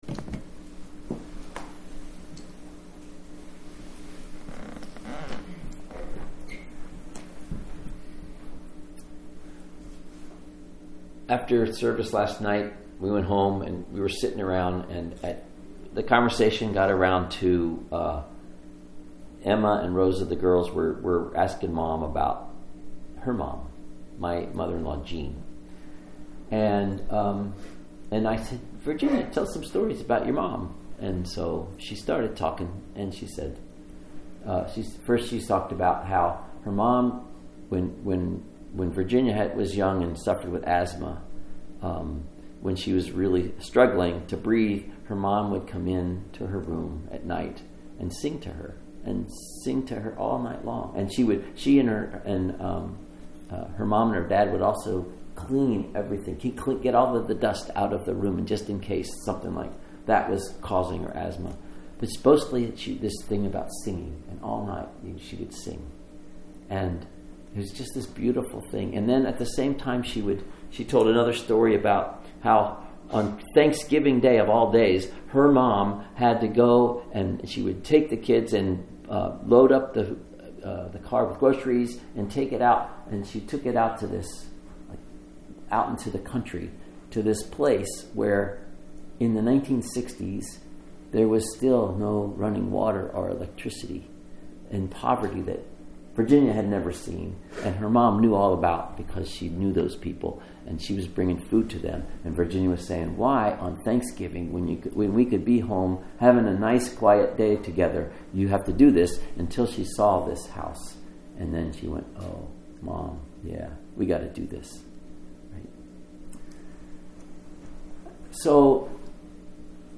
Sermons | Lake Chelan Lutheran Church
Nativity of Our Lord/Christmas Day Service